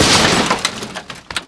pd_metal2.wav